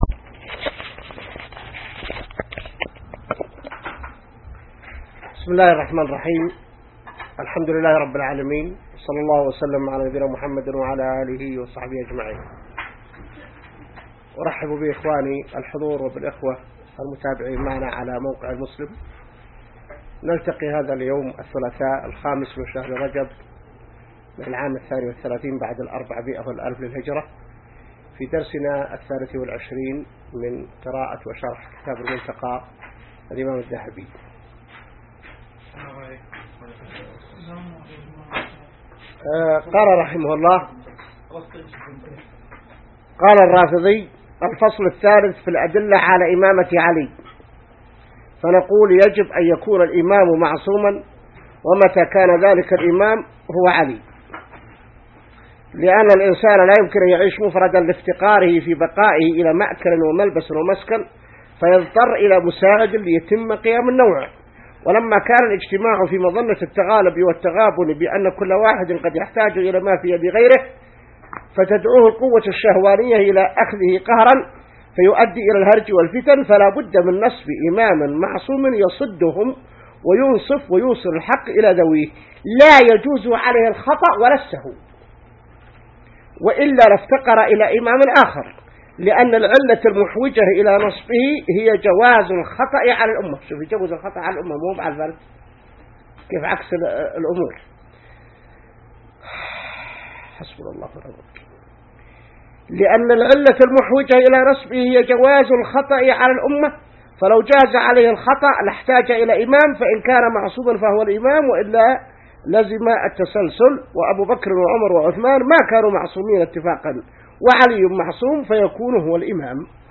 الدرس 23 من شرح كتاب المنتقى | موقع المسلم